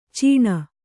♪ cīṇa